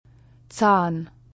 [ts] stimmlose alveolare Affrikate